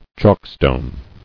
[chalk·stone]